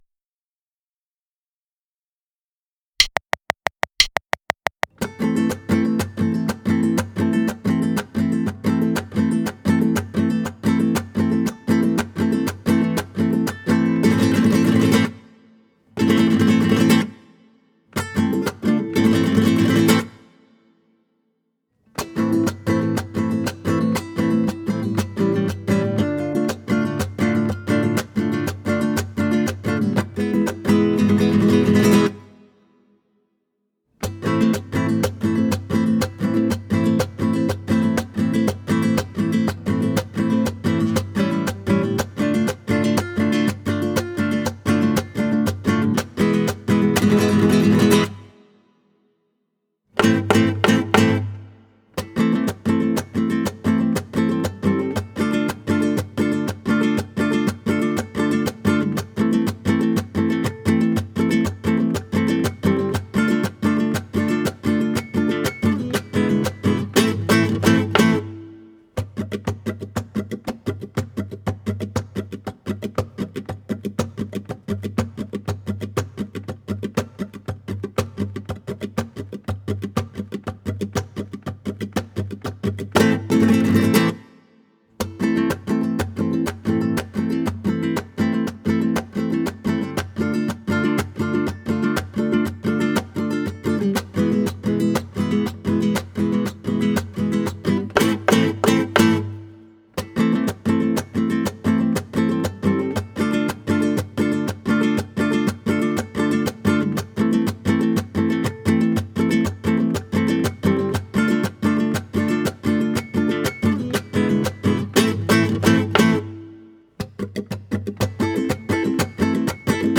گام قطعهAm
متر قطعه6/8
این قطعه در متر شش هشت و در گام Am نگارش شده.